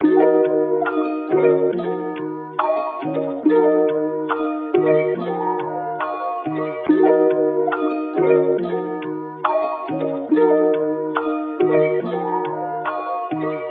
Tag: 140 bpm Hip Hop Loops Percussion Loops 2.31 MB wav Key : Unknown Ableton Live